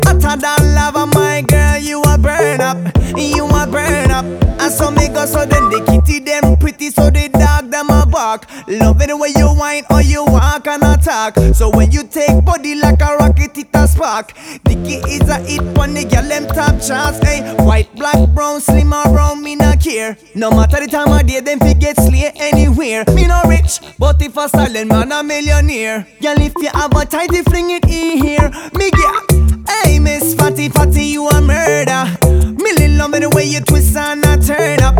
Жанр: Танцевальные
# Modern Dancehall